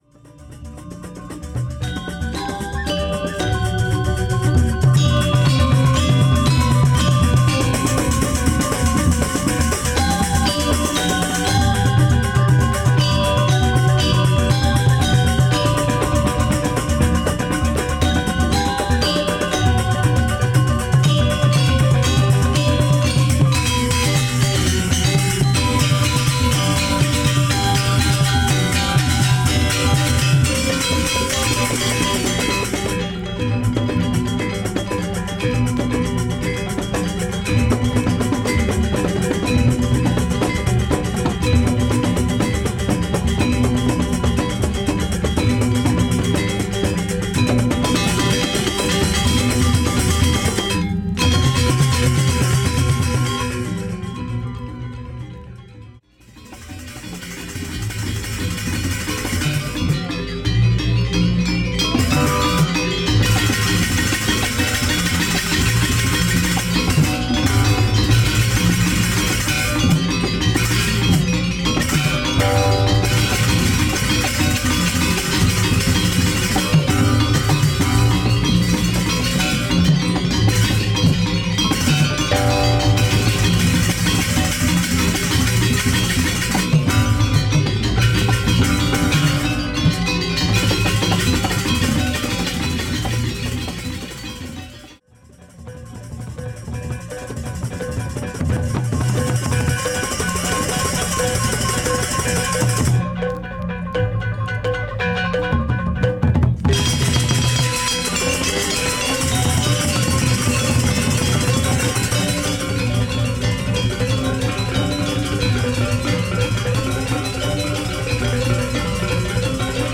Superb Indonesian music recorded by a bunch of French guys
in the mid 70's in Bali.